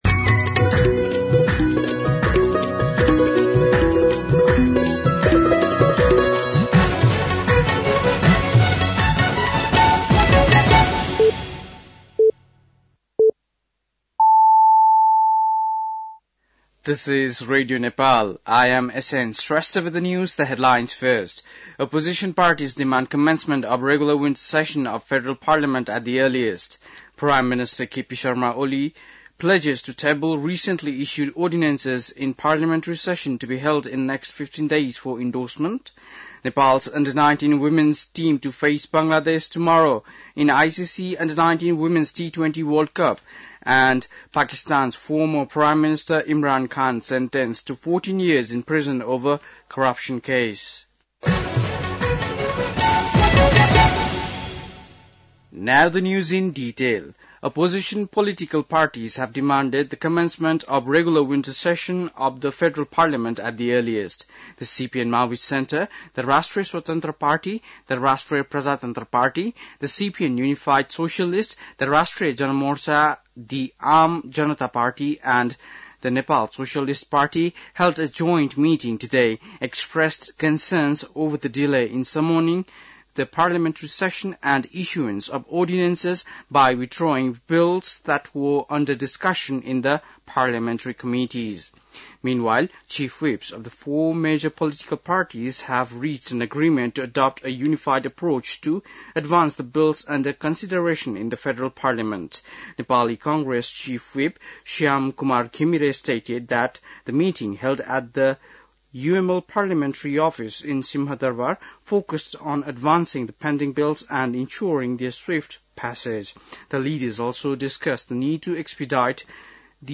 बेलुकी ८ बजेको अङ्ग्रेजी समाचार : ५ माघ , २०८१
8-PM-English-News-10-4.mp3